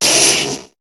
Cri de Sablaireau dans Pokémon HOME.